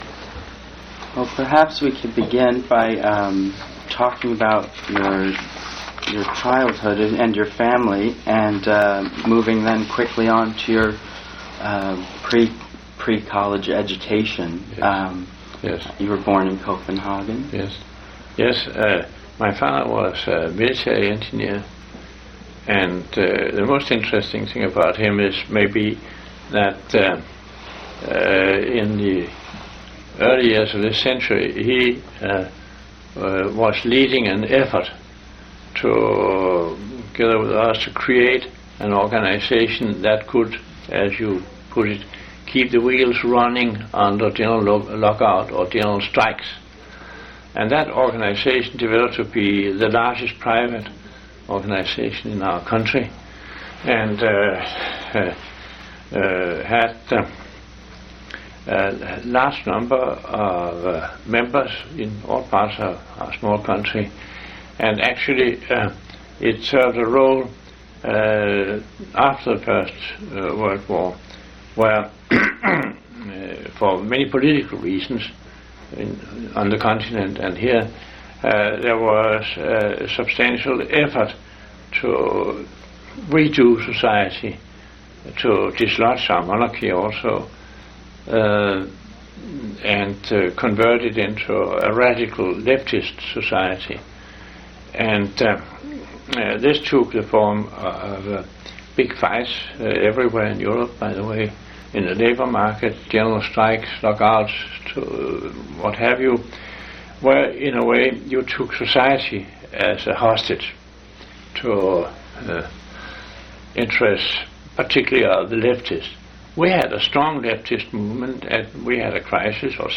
Oral history interview with Haldor F. A. Topsøe